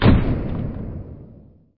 boom.mp3